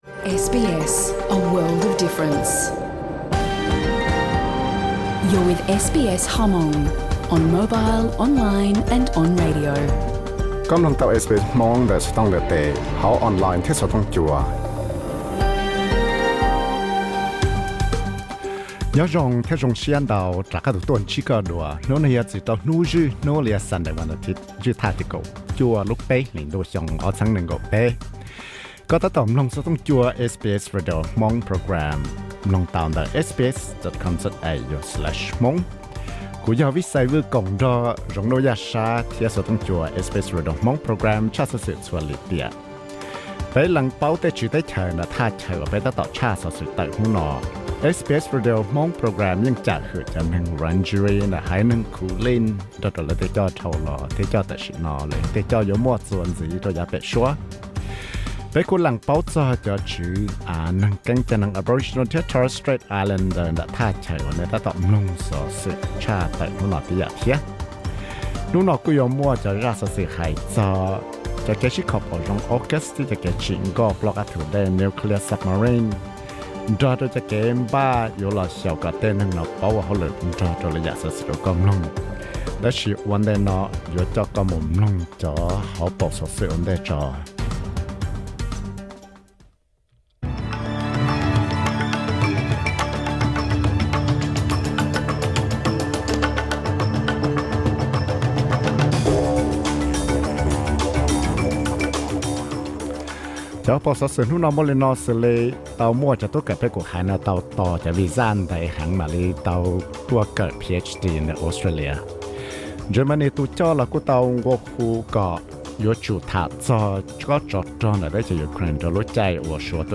Xov xwm hnub zwj Hnub (Sunday news 19.03.2023), AUKUS nuclear submarines, AUKUS build, wealth attitude, Japan-South Korea security cooperation.